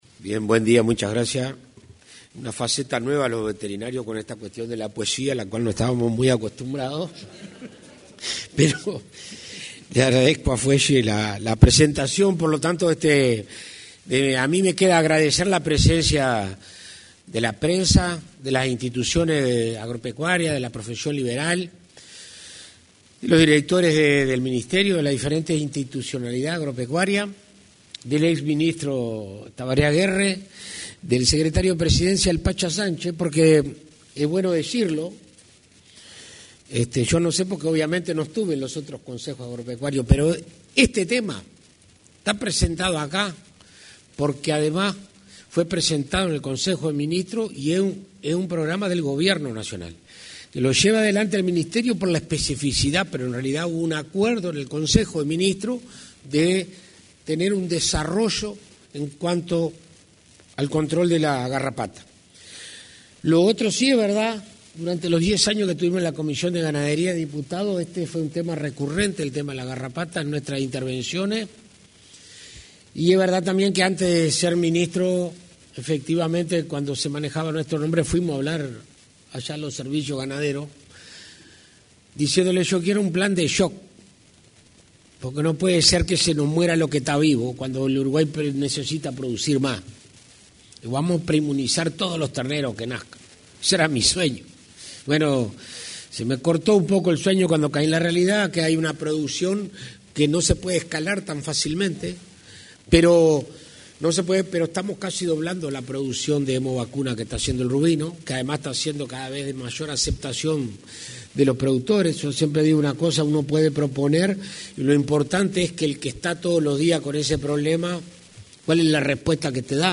Palabras del ministro de Ganadería, Alfredo Fratti
Palabras del ministro de Ganadería, Alfredo Fratti 26/08/2025 Compartir Facebook X Copiar enlace WhatsApp LinkedIn Este martes 26, durante la presentación del Plan Nacional de Lucha contra la Garrapata, realizada en el auditorio del anexo a la Torre Ejecutiva, se expresó el ministro de Ganadería, Agricultura y Pesca, Alfredo Fratti.